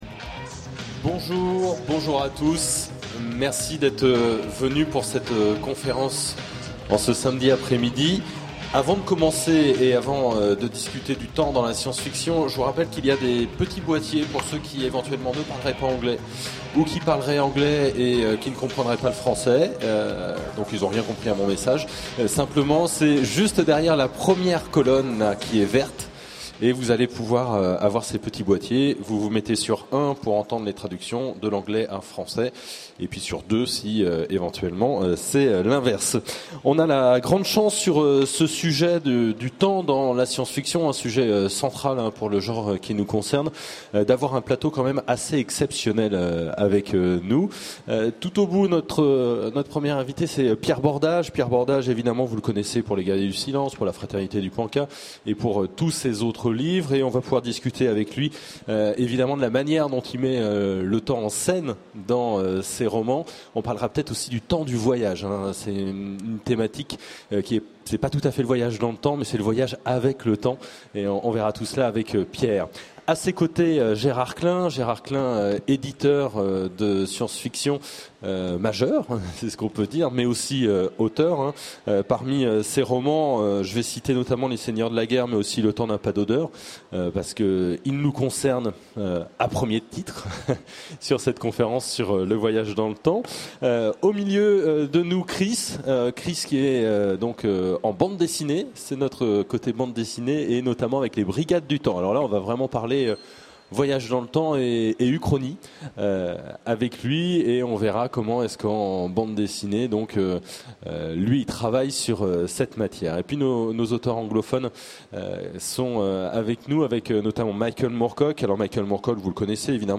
Utopiales 12 : Conférence La Nuit des Temps